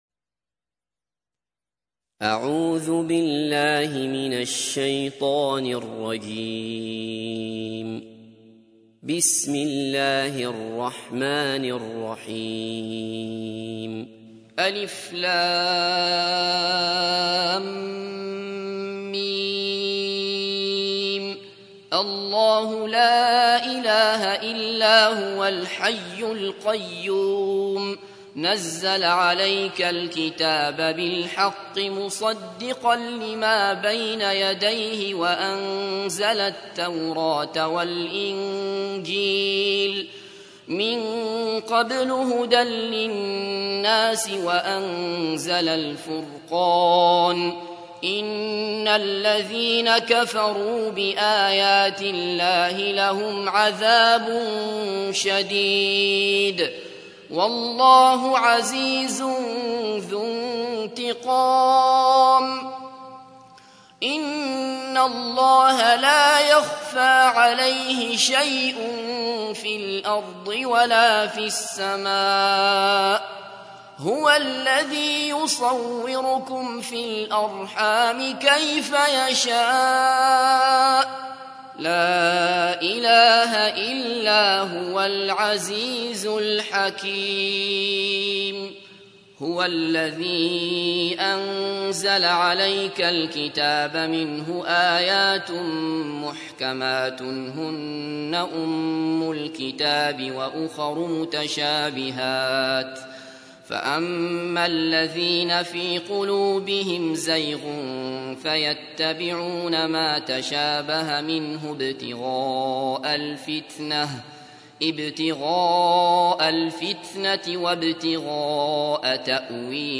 تحميل : 3. سورة آل عمران / القارئ عبد الله بصفر / القرآن الكريم / موقع يا حسين